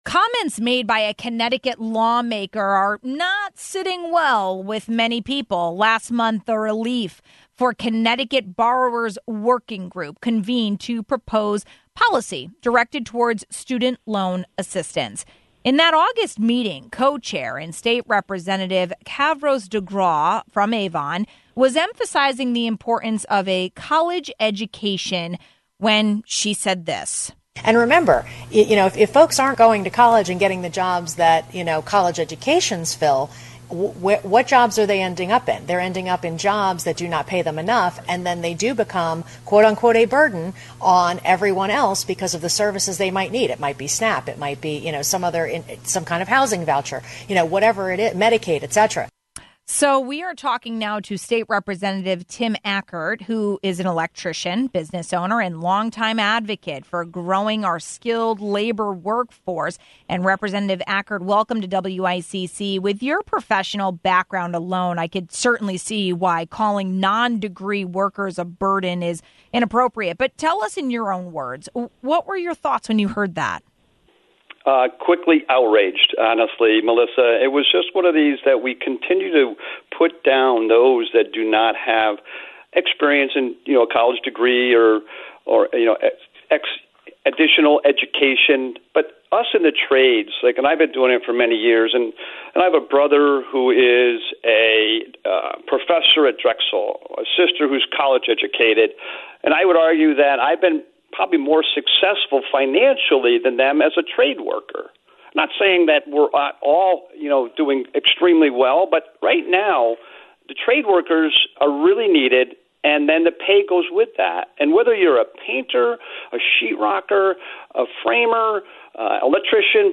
We spoke about it with State Representative Tim Ackert, an electrician, business owner, and longtime advocate for growing our skilled labor workforce.